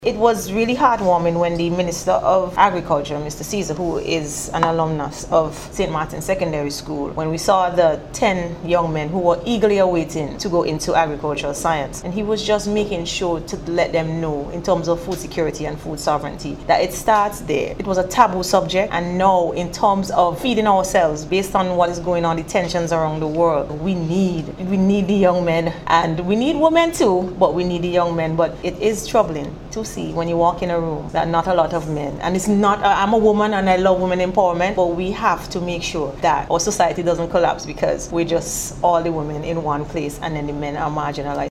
Speaking at GECCU’s recent bursary award ceremony